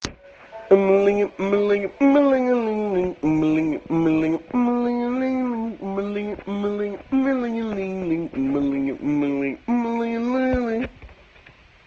Play guitarra humana